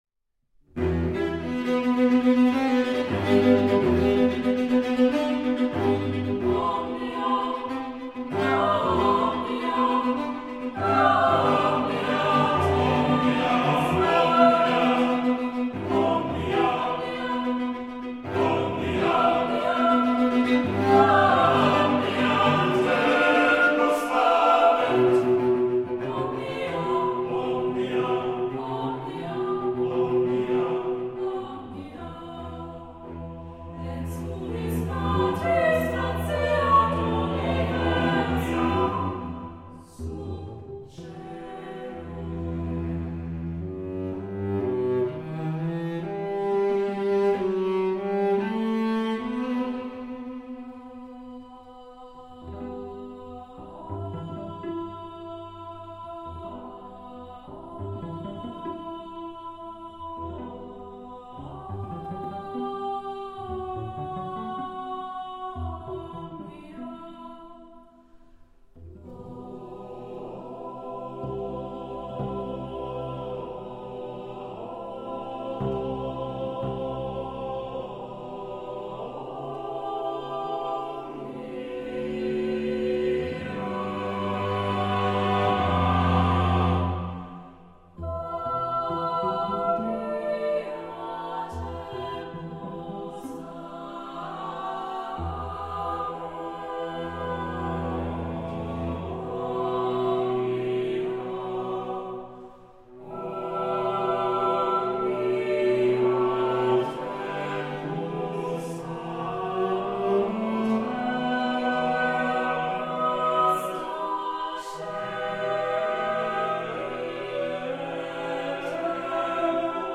für achtstimmigen Chor und Violoncello
Impressionen von der Aufnahme im Studio